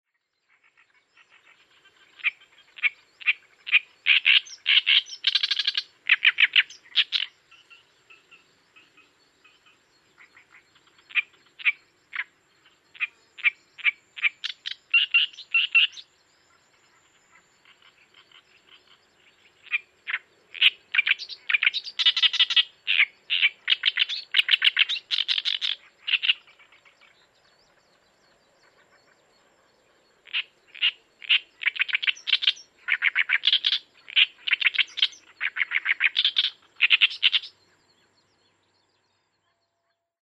ooyoshikiri_s1.mp3